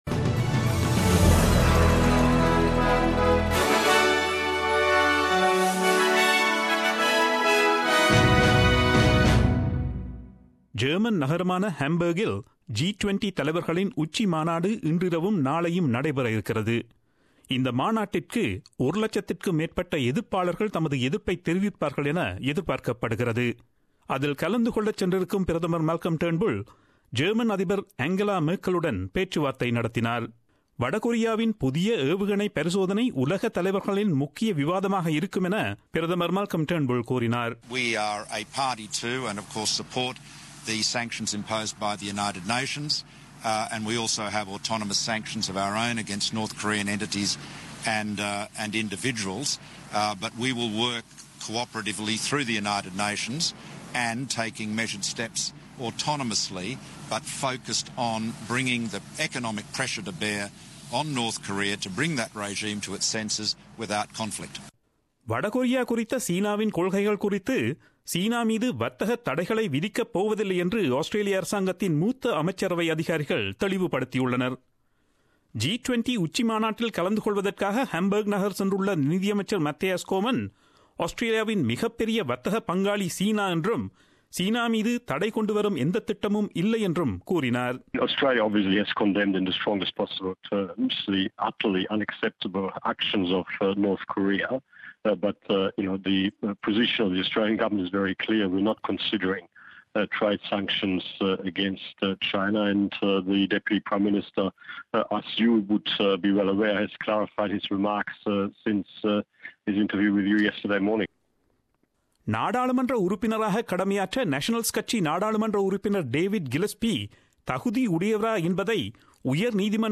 Australian News 07/07/2017